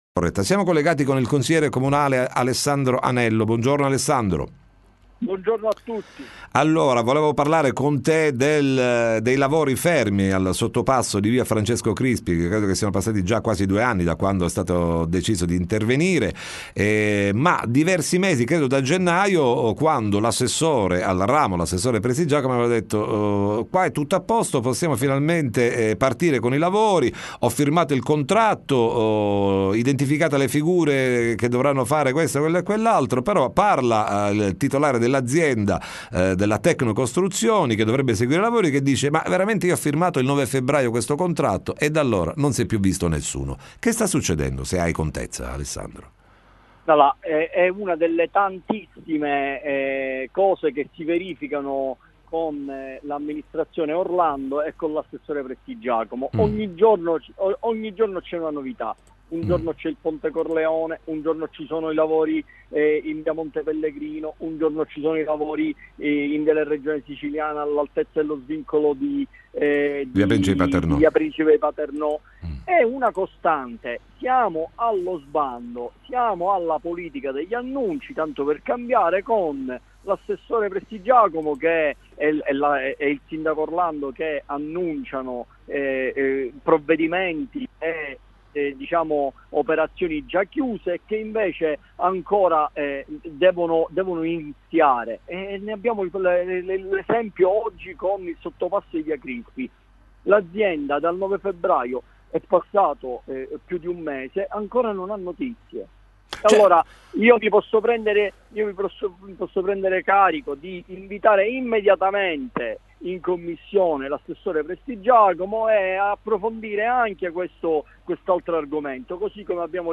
TM intervista il consigliere A. Anello